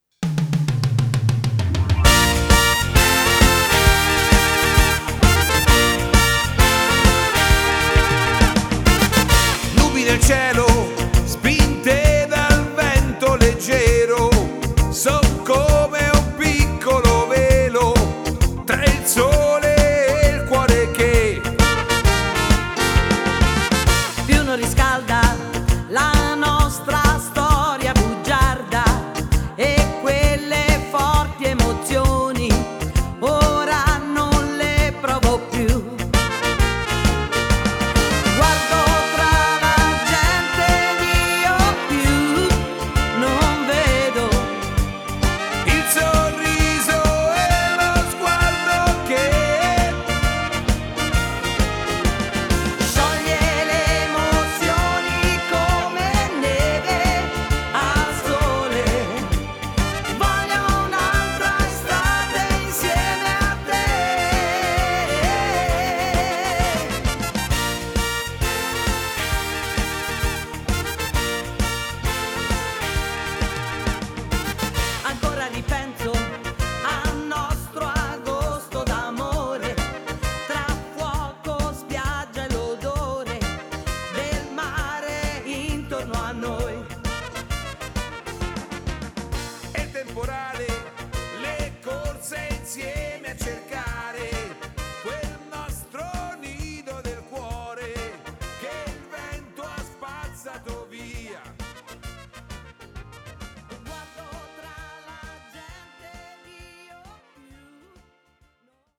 Rumba
12 brani ballabili, 10 inediti e due cover: